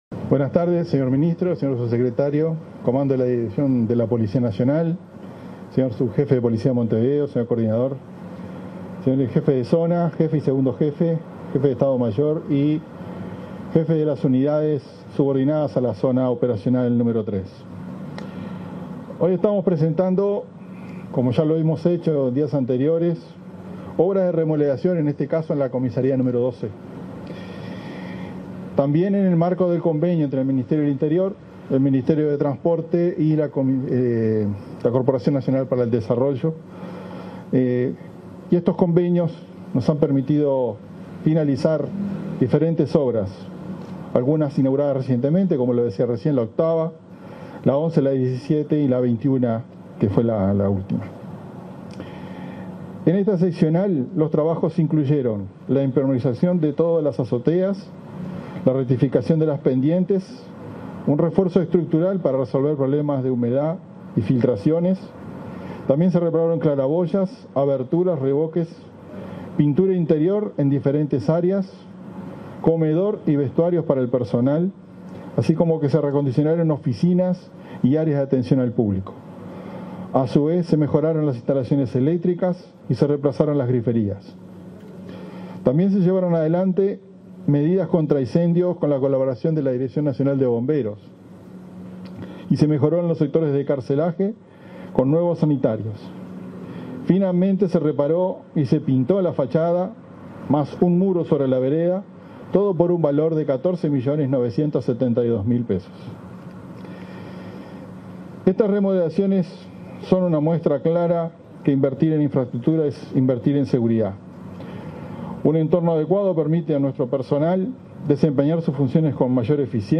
Palabras del jefe de Policía de Montevideo, Mario D´Elía
En el marco de la reinauguración de la Seccional 12ª., este24 de febrero, se expresó el jefe de Policía de Montevideo, Mario D´Elía.